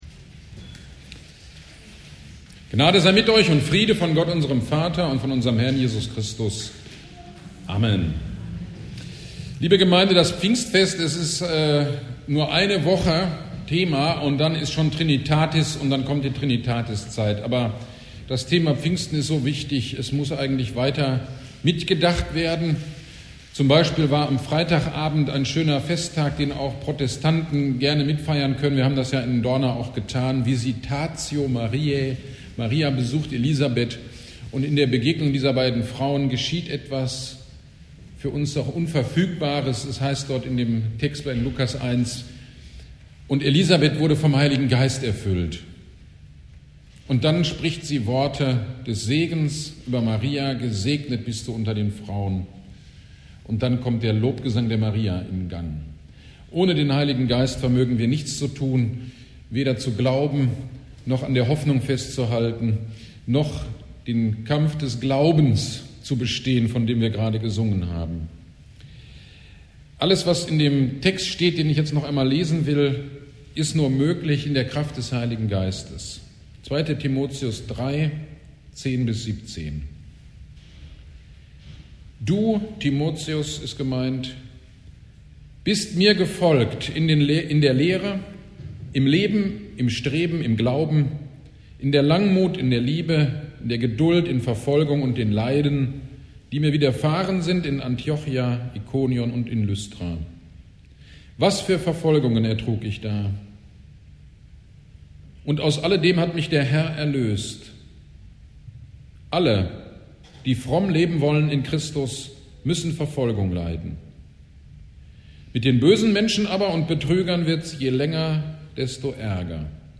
GD am 02.06.24 Predigt zu 2. Timotheus 3, 10-17